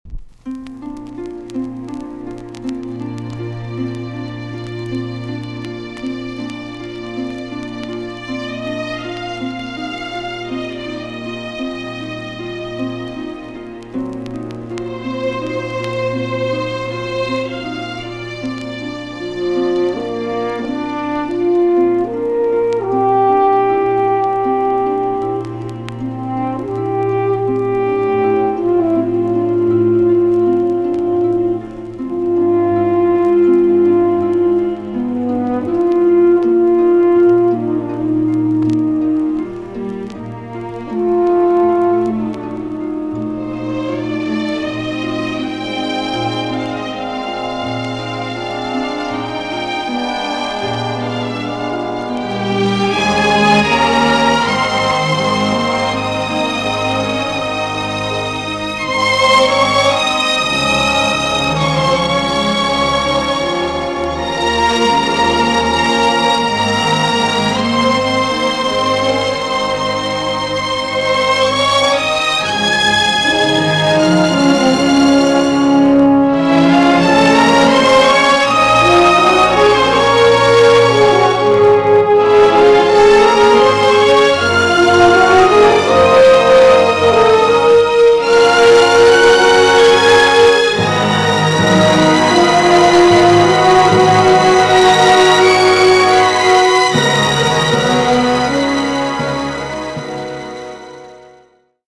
B.SIDE INST
EX-~VG+ 少し軽いチリノイズがありますが良好です。